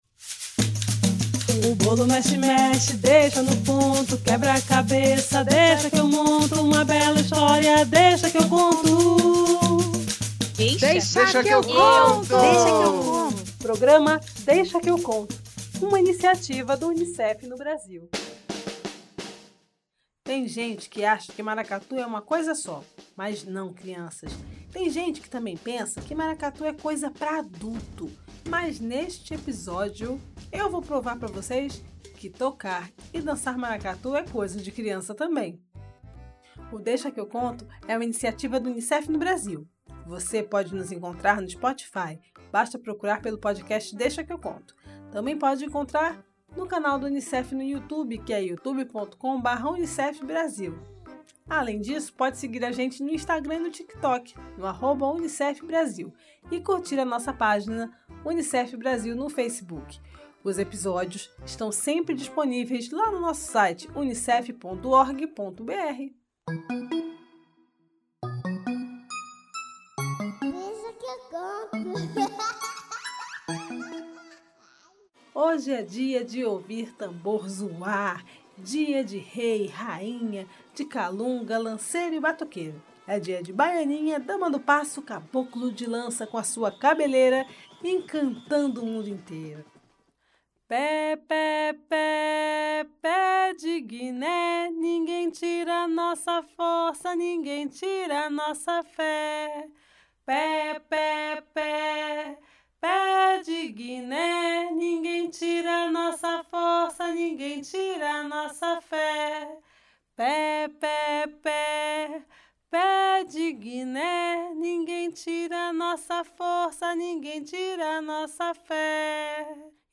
Além de muita conversa, vamos ouvir e aprender as loas (as músicas) e ouvir uma bela história sobre a corte real do Maracatu.
Entrevista